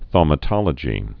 (thômə-tŏlə-jē)